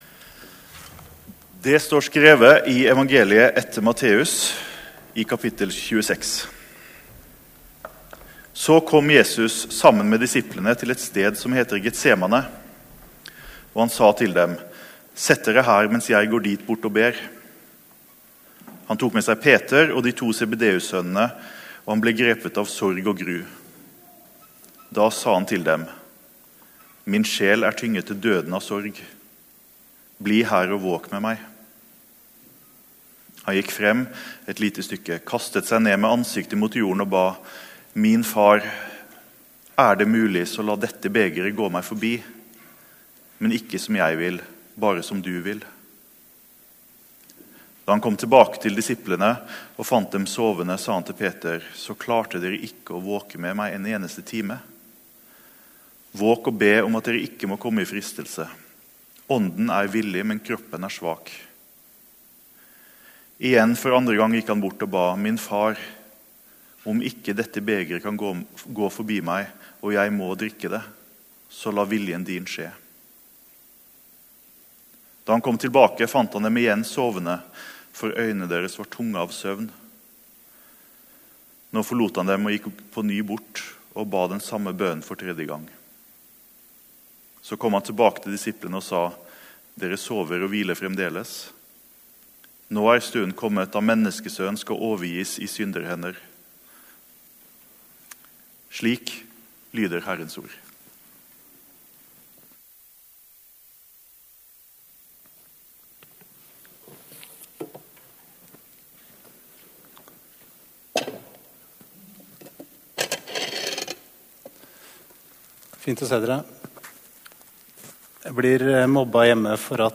Gudstjeneste 6. mars 2022,- Jesus i Getsemane | Storsalen